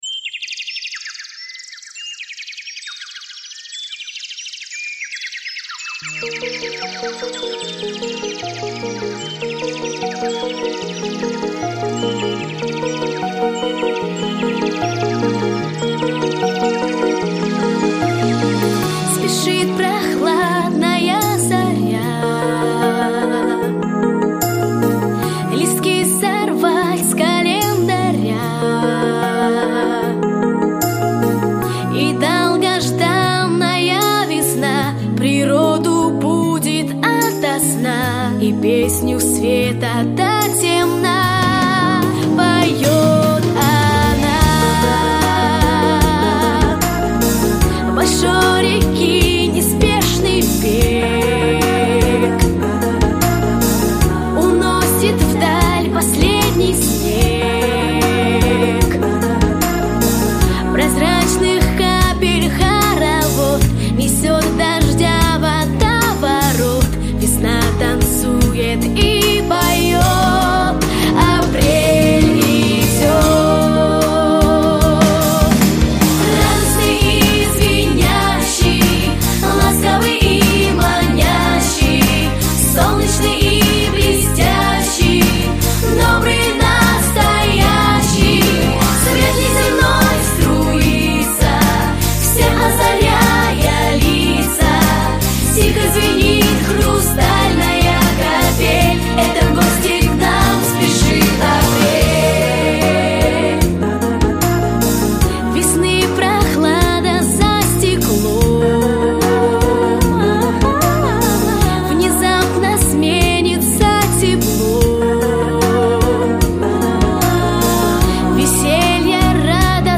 Песенки про весну Текст песни